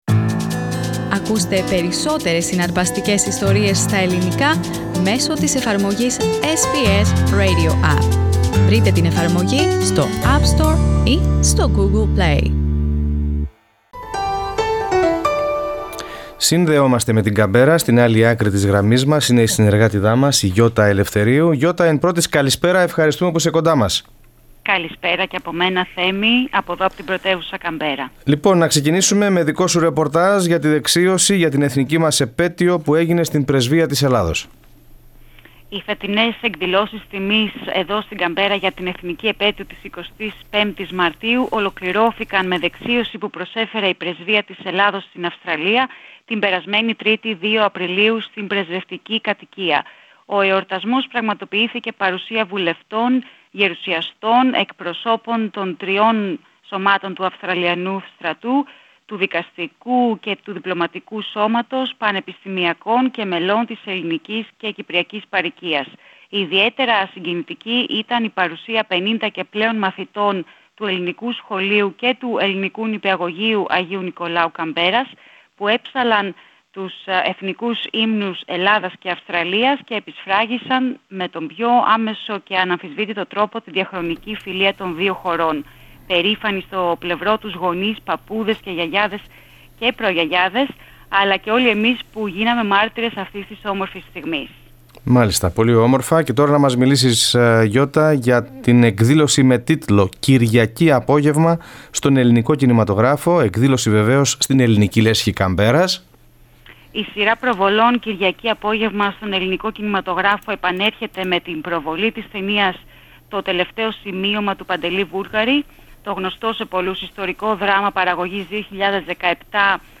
News and stories from our weekly correspondence